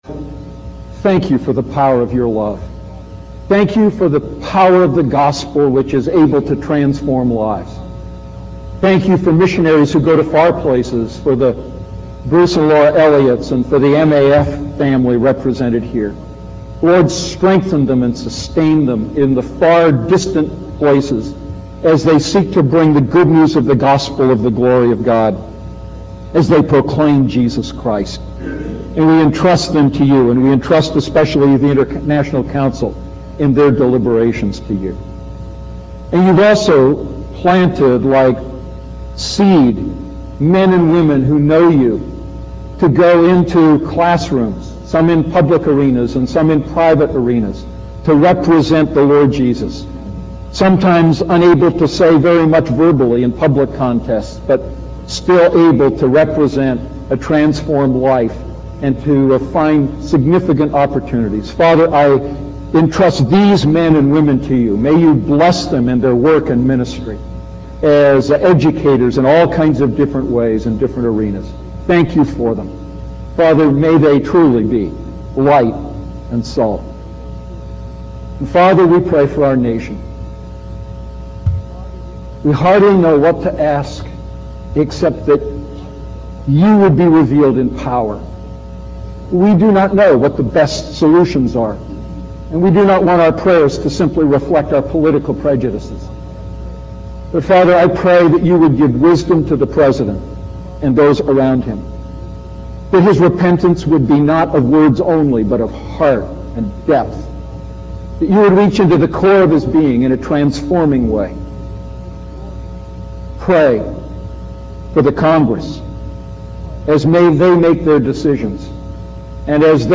A message from the series "Ephesians Series."